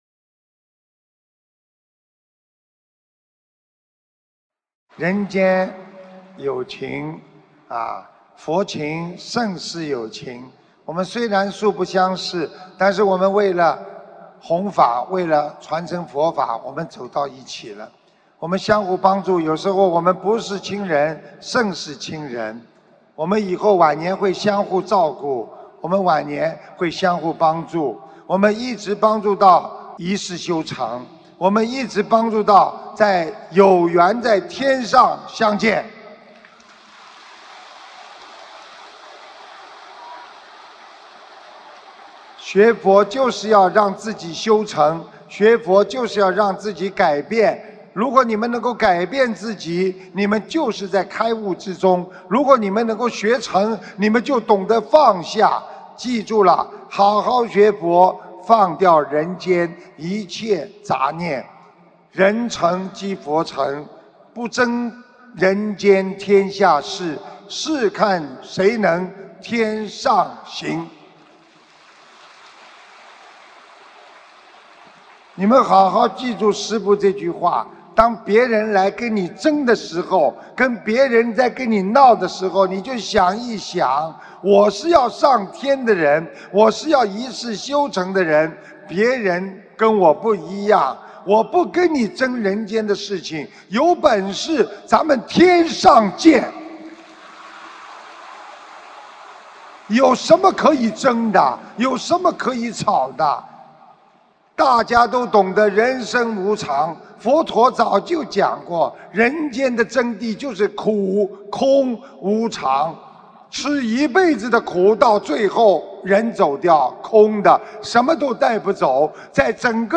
2018年3月12日印尼雅加达法会感人结束语-经典开示节选